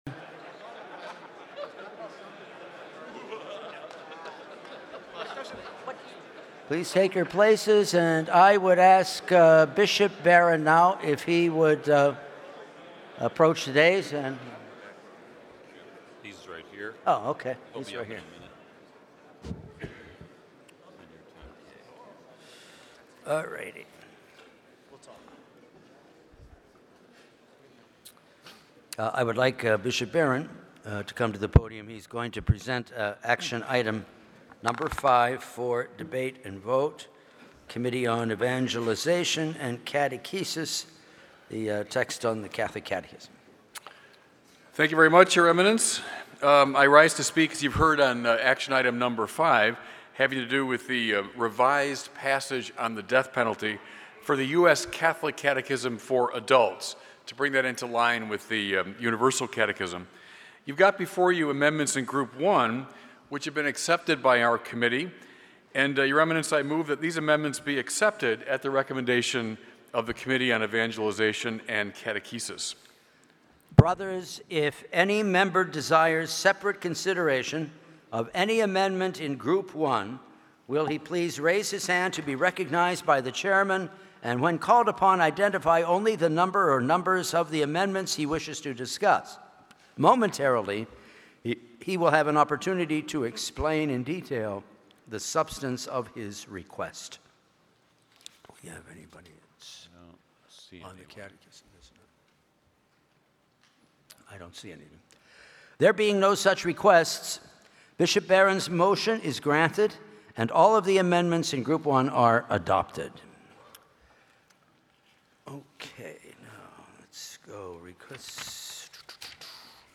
Index of /2019 USCCB Spring Assembly/2019 USCCB Thursday Morning Session